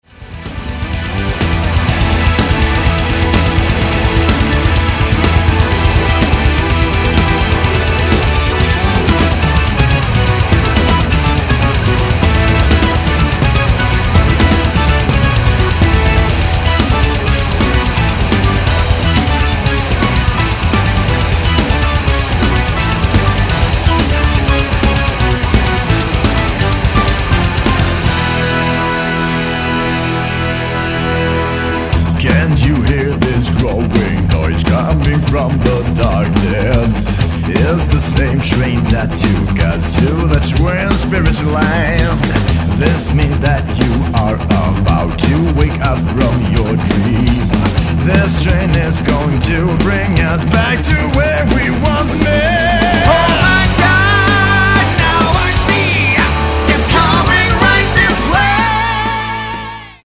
(low quality)